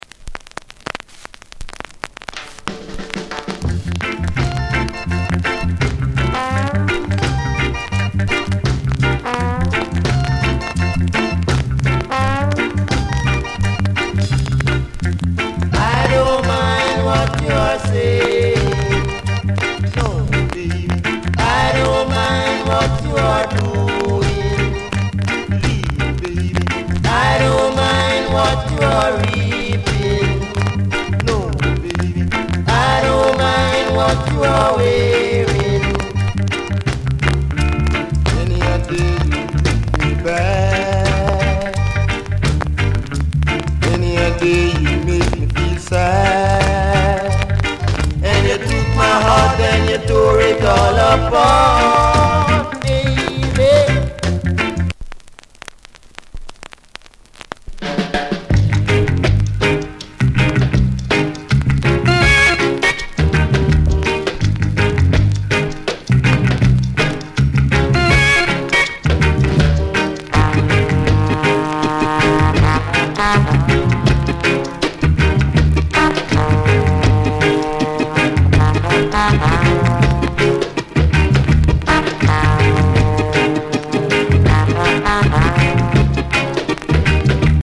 Notes: (crack on start)